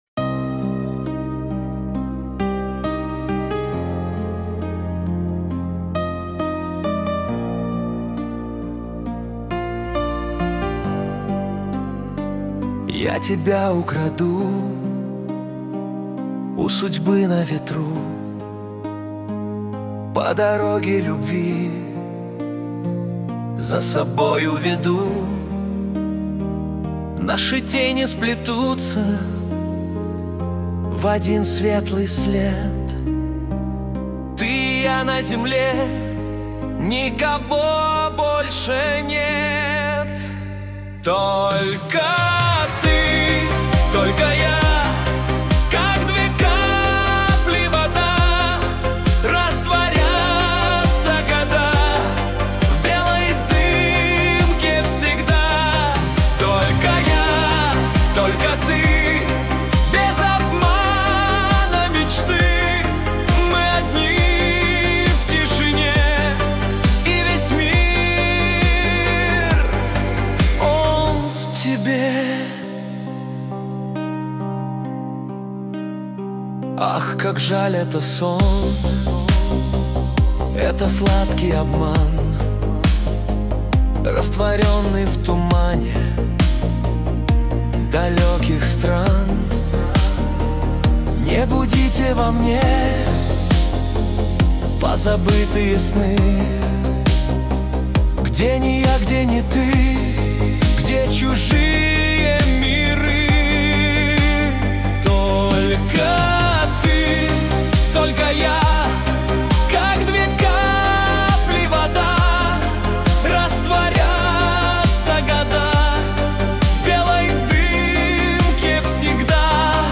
Альбом: шансон